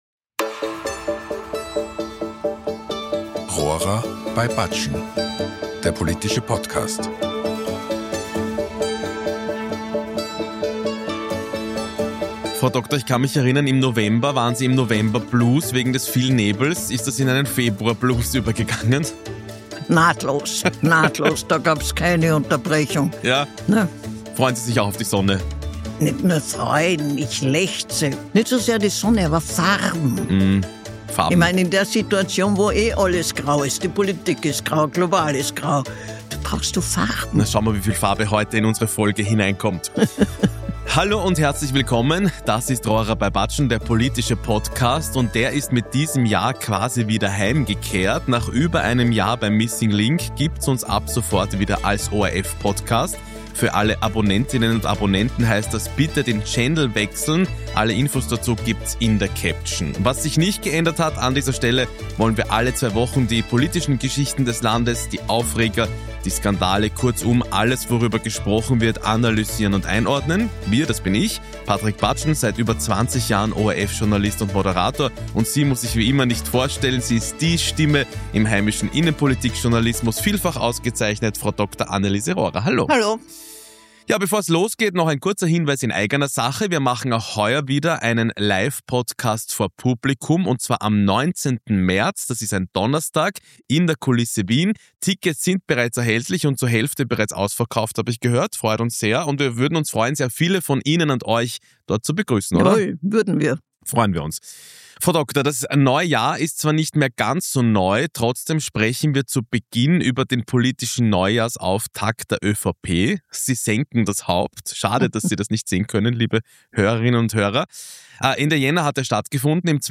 In der neuesten Folge von "Rohrer bei Budgen" analysiert Innenpolitik-Journalistin Anneliese Rohrer bei ORF-Moderator Patrick Budgen die Rede von ÖVP-Chef Christian Stocker beim Neujahrsauftakt der ÖVP. Die Idee einer Volksbefragung zum Thema Wehrpflicht kann Rohrer nichts abgewinnen. Weitere Themen: der Wöginger-Prozess und die Comeback-Gerüchte rund um Christian Kern.